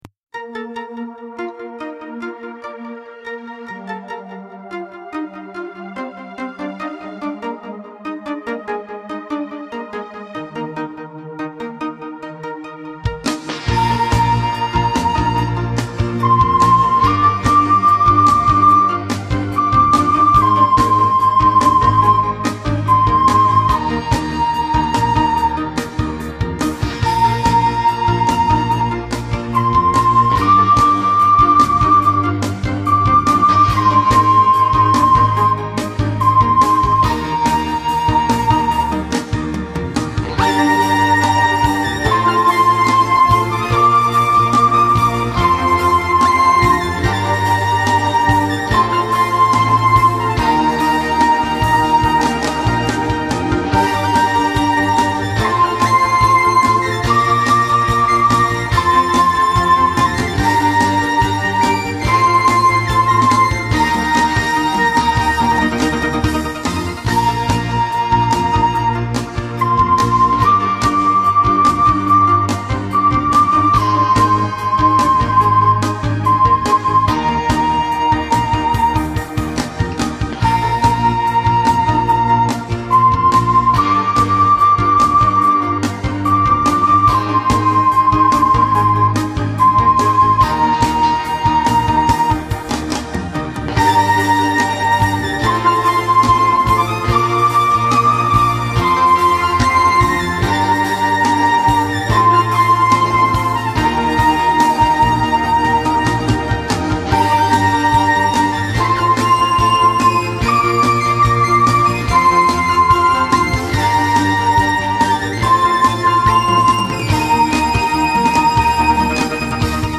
Three Star Cafe (medium).mp3